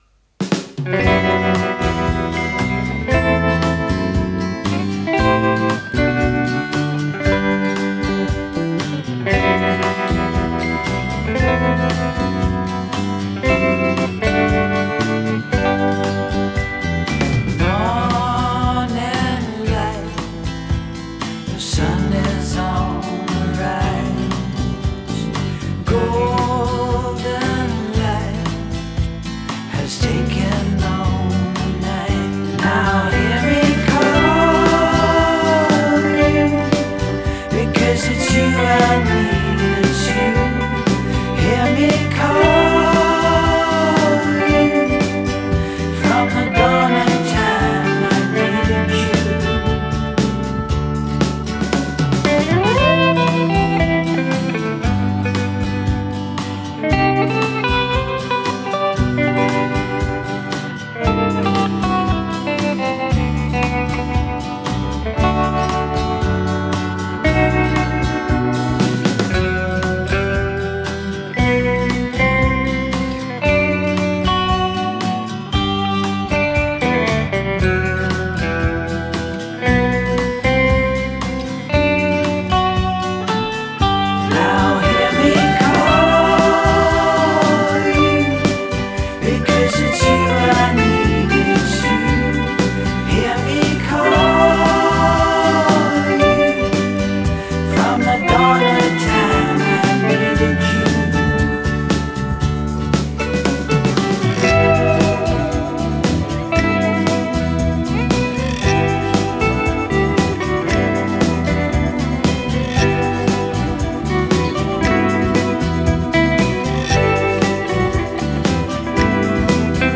I'm attaching a song I just finished that has the Am chord in it but never played for more than a couple of bars at any time. 2x acoustic tracks L and R with no eq on them.